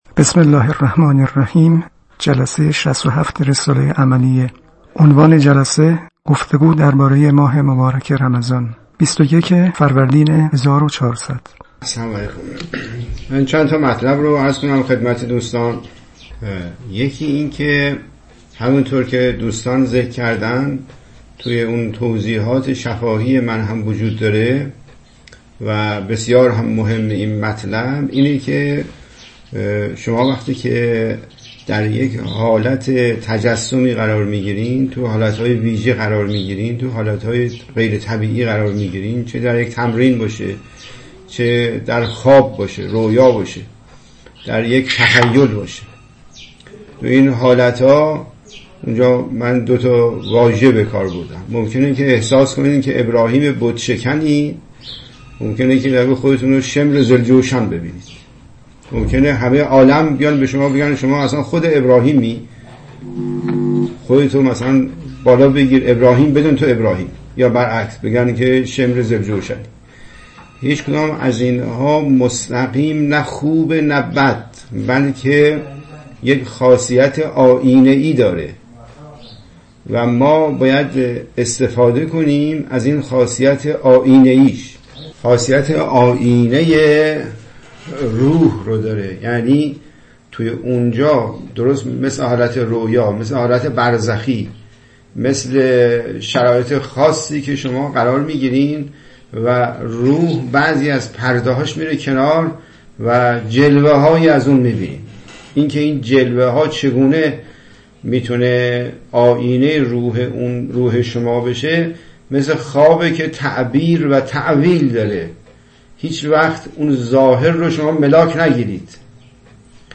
گفت‌وگو دربارۀ رمضان (انجام تمرین روزه‌داران کوهستان یمن) (۱)